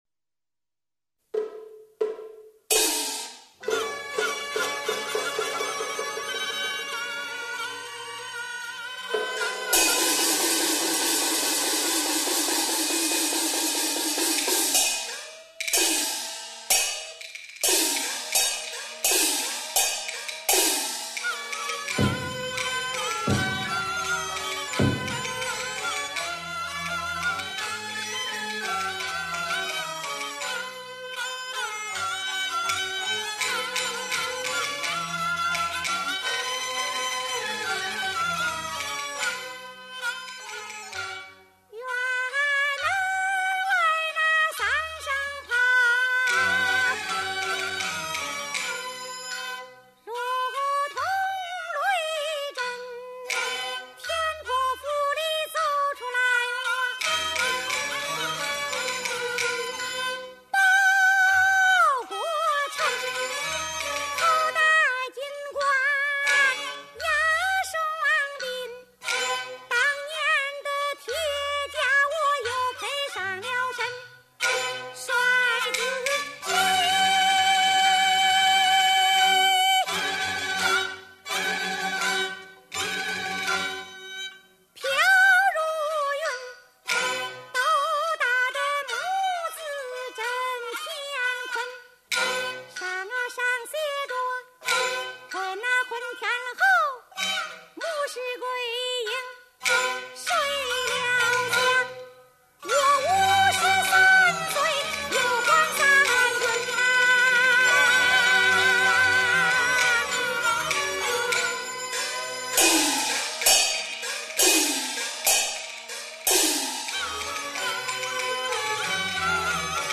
以小嗓为主，大小嗓结合运用。